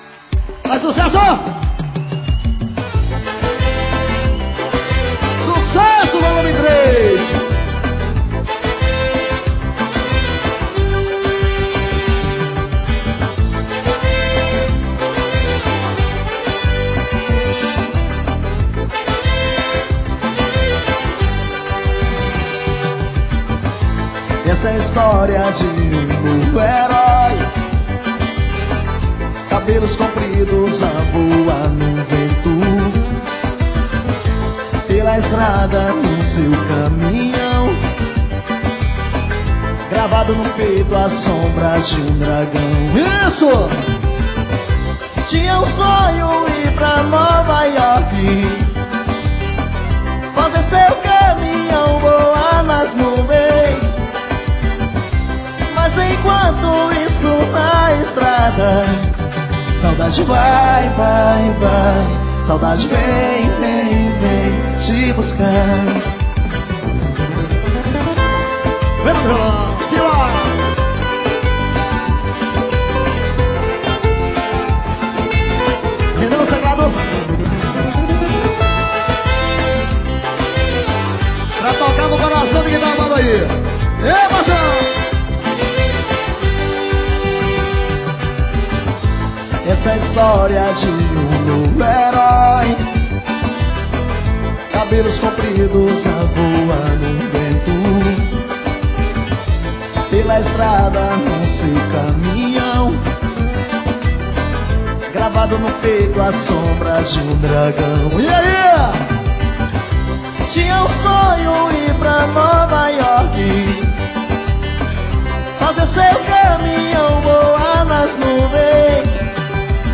Arrocha.